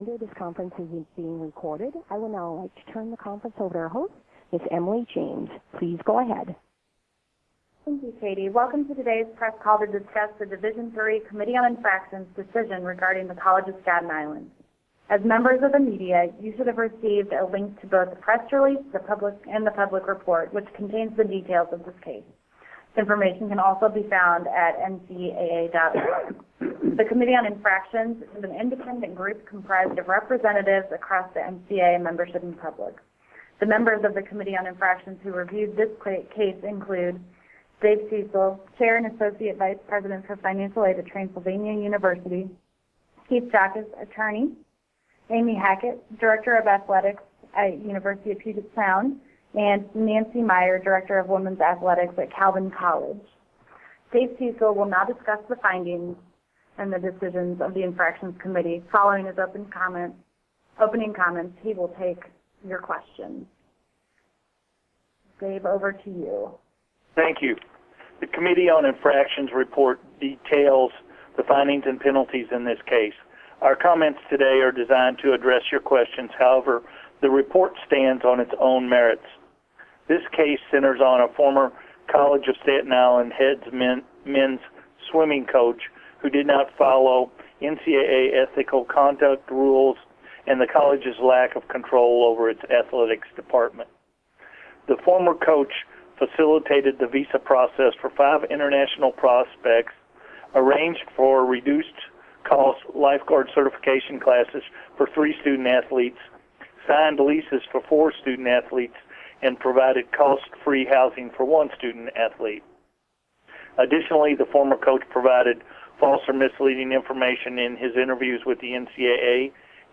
NCAA Division III Committee on Infractions Media Teleconference regarding the College of Staten Island